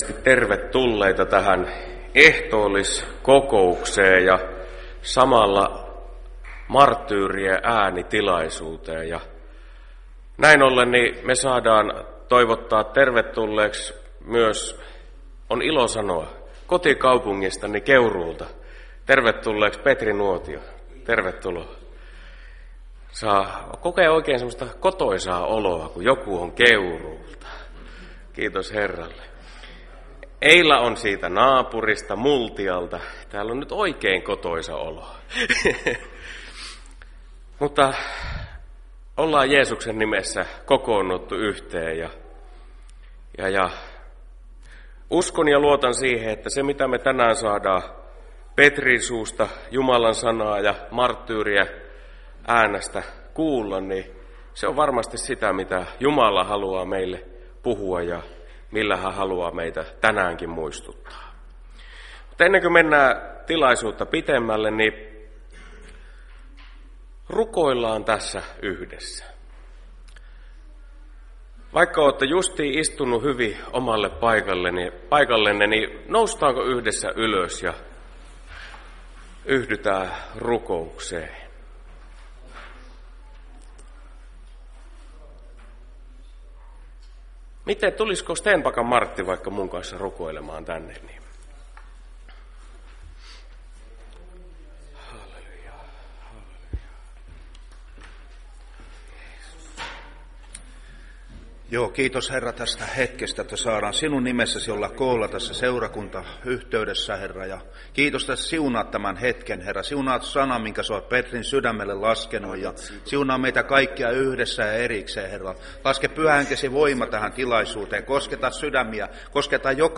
Ehtoolliskokous 5.12.2021